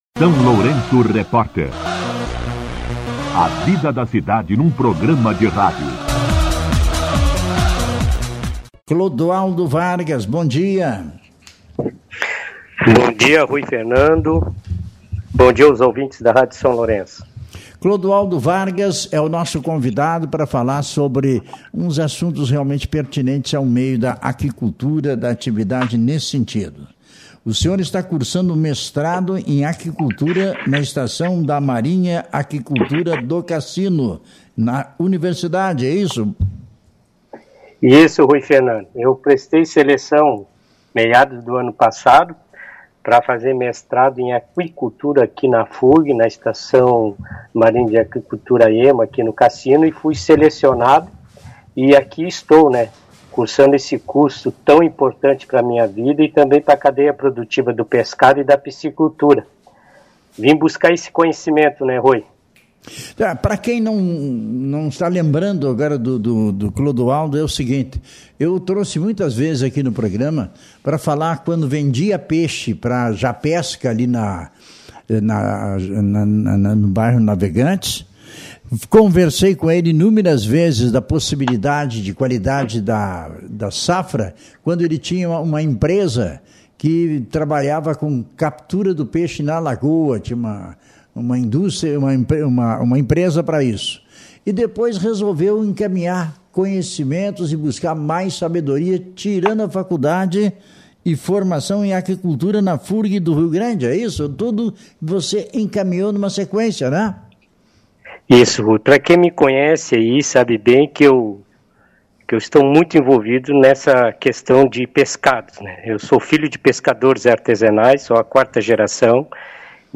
No entanto, em entrevista ao SLR RÁDIO desta terça-feira (27), destacou as dificuldades enfrentadas pelo setor, com pescadores que não estão conseguindo garantir o sustento por meio da atividade.